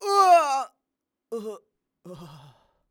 xys死亡6.wav 0:00.00 0:02.91 xys死亡6.wav WAV · 250 KB · 單聲道 (1ch) 下载文件 本站所有音效均采用 CC0 授权 ，可免费用于商业与个人项目，无需署名。
人声采集素材